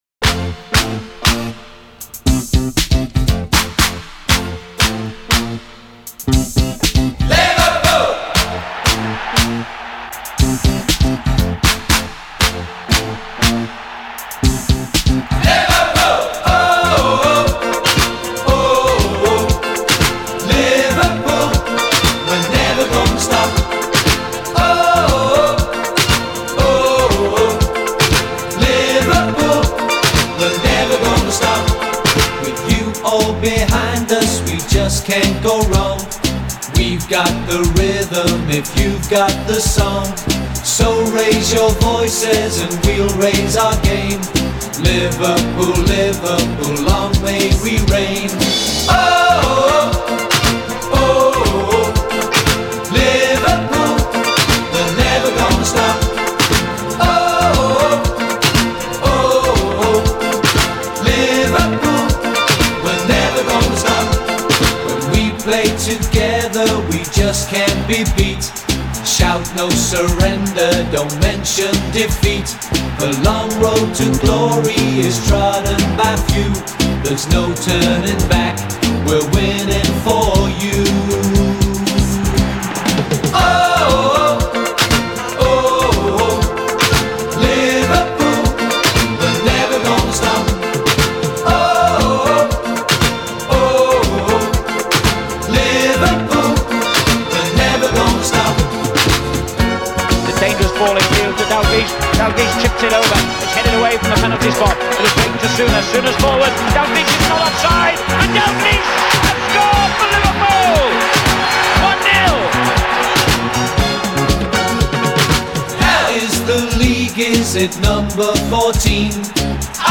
Песни болельщиков: